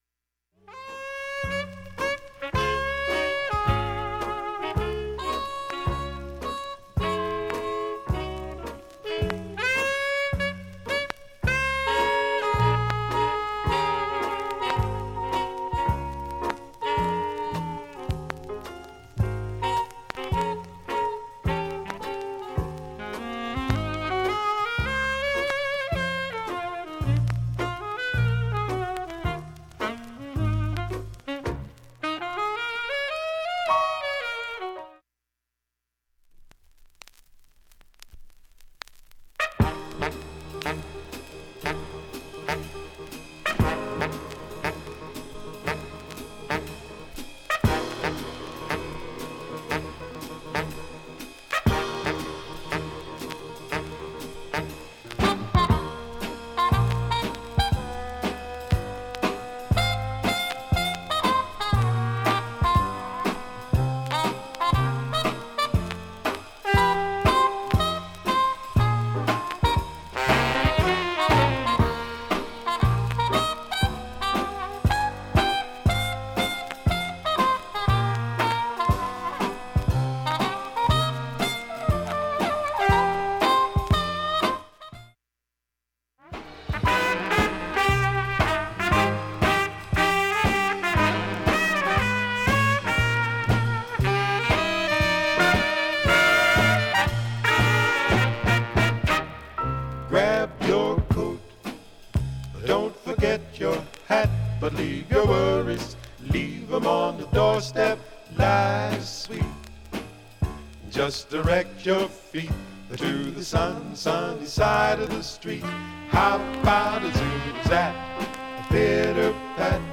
書き込み ◆盤質Ａ面/ほぼEX ◆盤質Ｂ面/ほぼEX 概況 薄いスレがある程度で問題はありません。